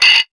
176UKPERC2-L.wav